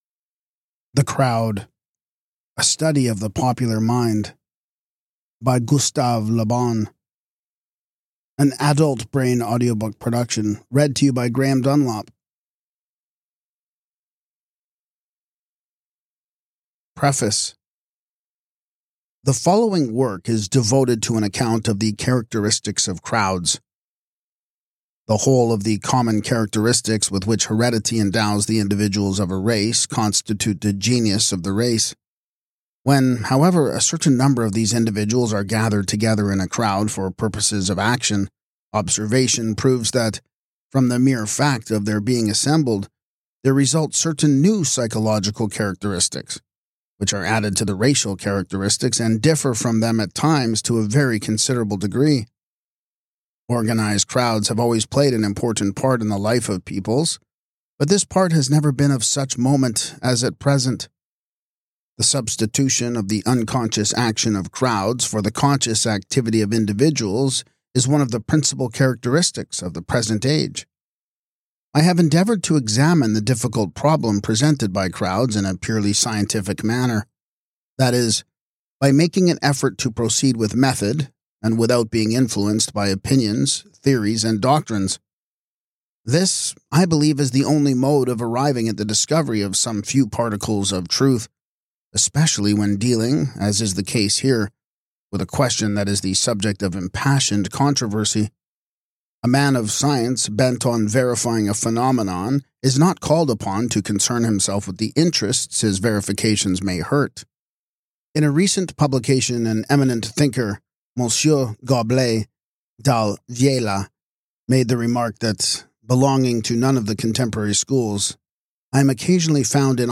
🔹 Key Insights from This Audiobook: